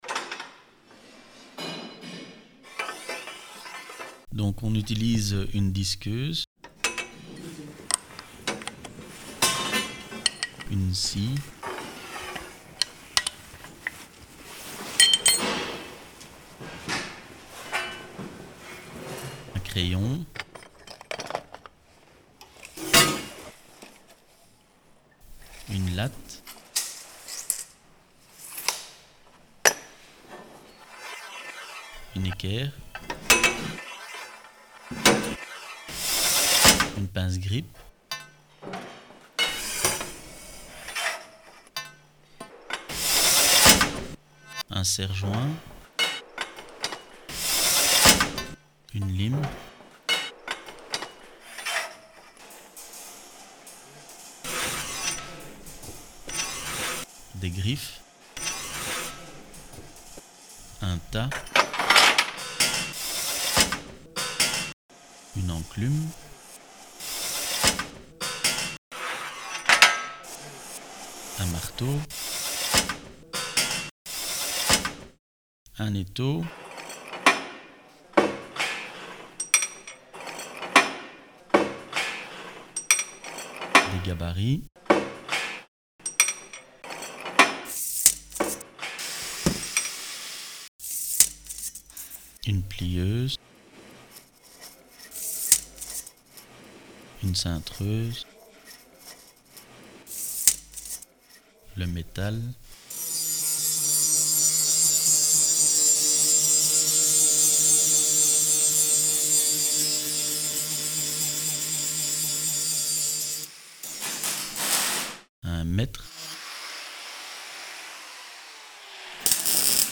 Composition
Ferronerie, smeedwerk, rue du Monténégro straat 54.